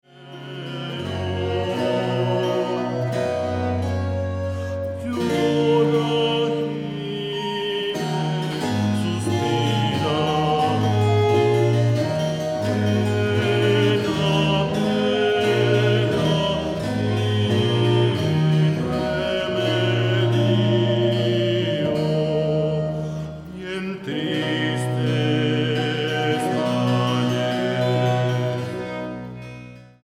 flautas de pico